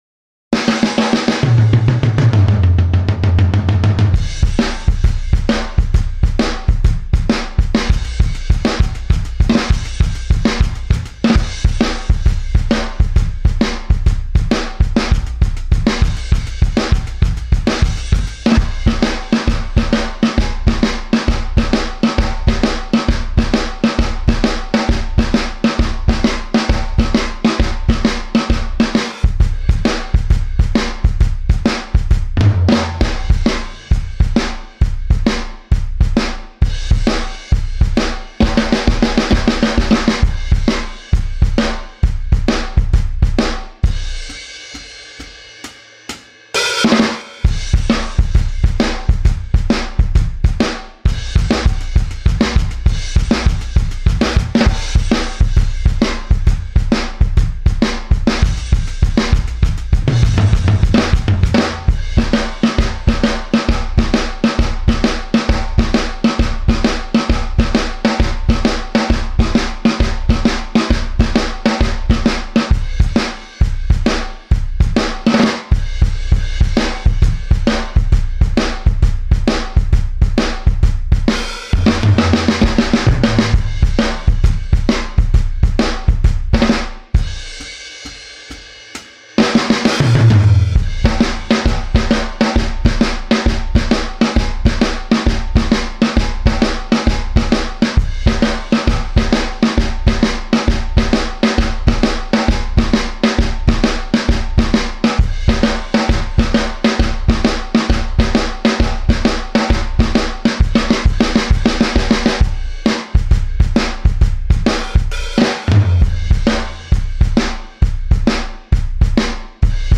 Studio Kit